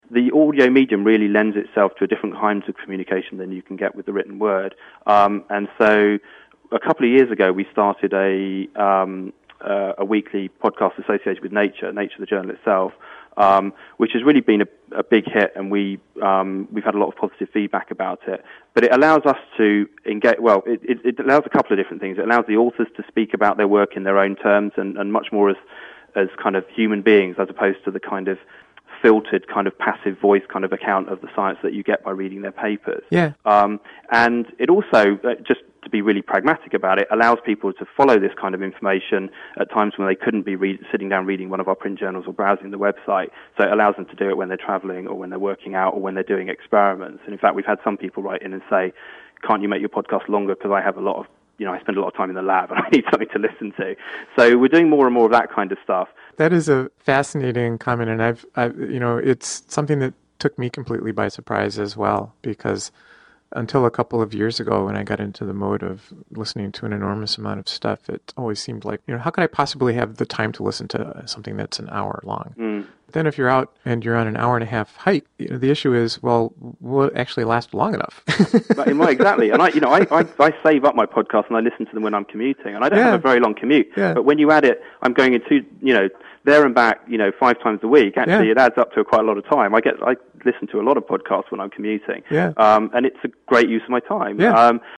This is an excerpt from an interview covering Nature's efforts to advance science and scientific collaboration using the Internet.